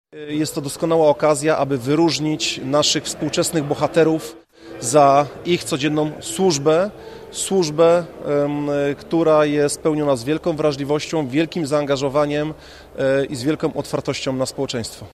Lubuscy strażacy wzięli dziś udział w uroczystym apelu z okazji obchodzonego niedawno Narodowego Święta Niepodległości. Dzisiejsza uroczystość była także okazją, by wyróżniającym się strażakom wręczyć odznaczenia i awanse.
Mówi Lubuski Komendant Wojewódzki Patryk Maruszak: